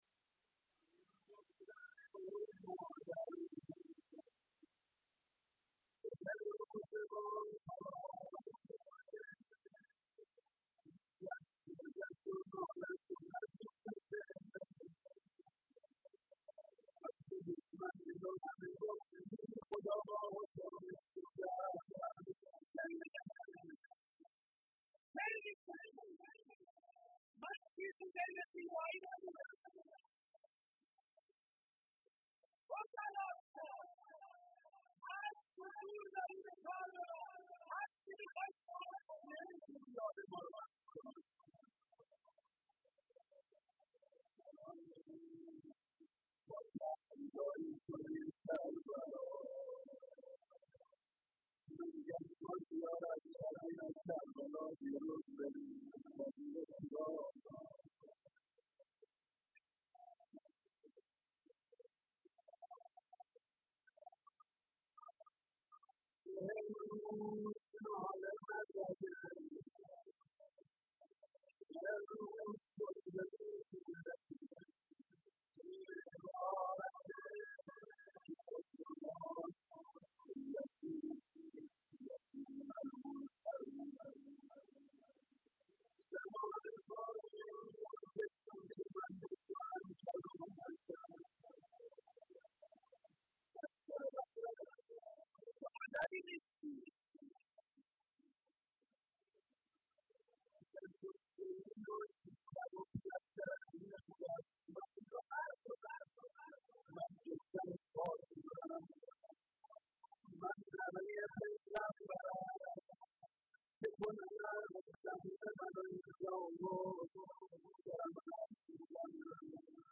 روضه
روضه و ذکر